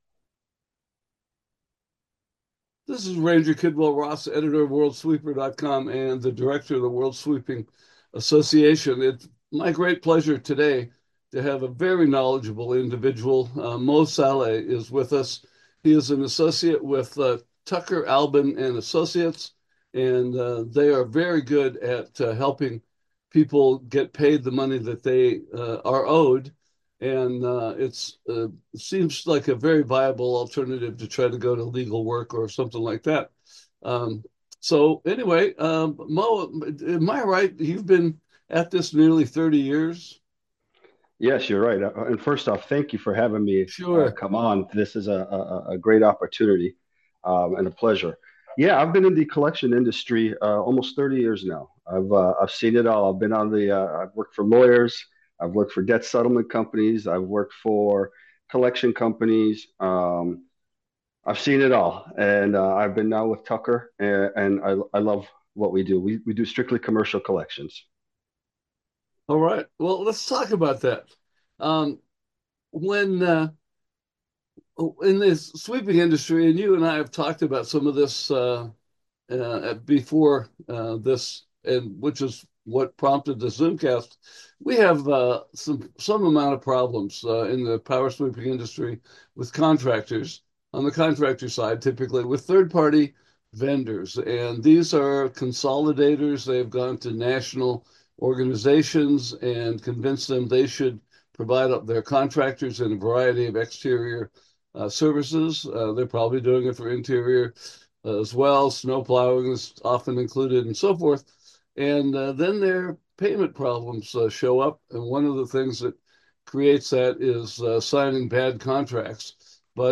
Collection Basics: An Interview with a Collections Pro with @30 Years of Experience